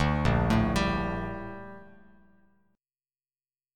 A#M13 Chord